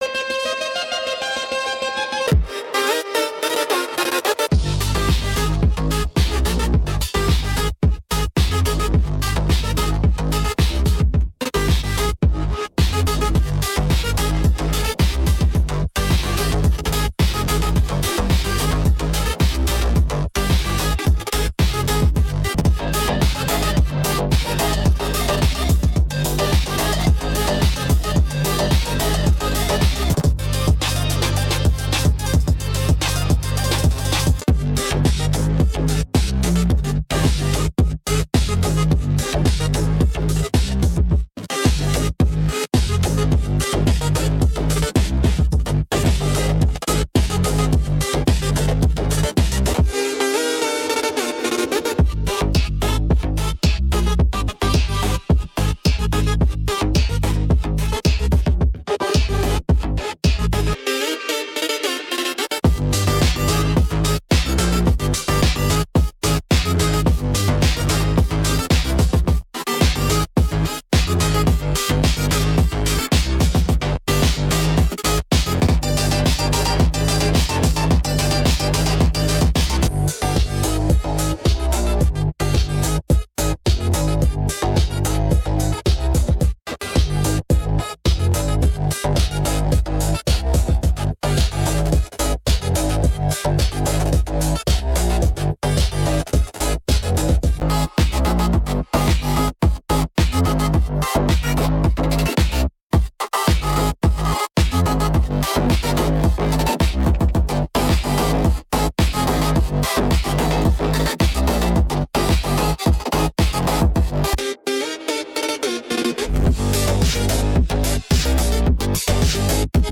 There are no lyrics because it is an instrumental.